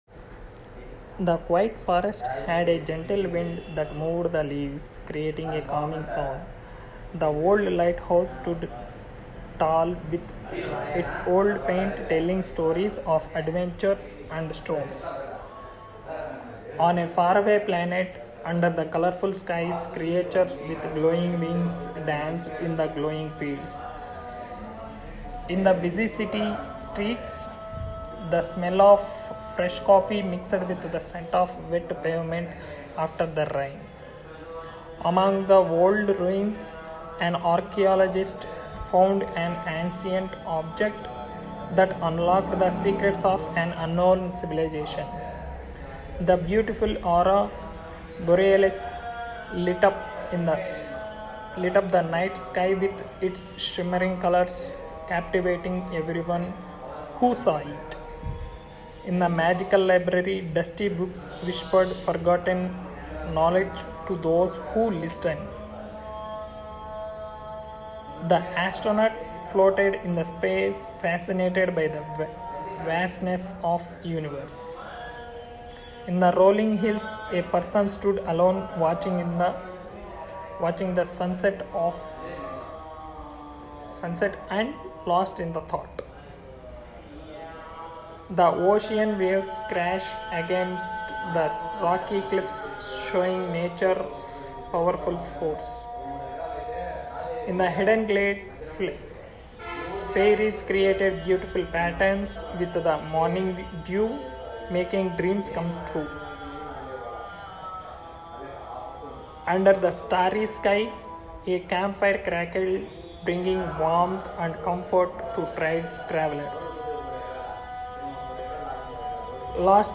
English-with-Noise.MP3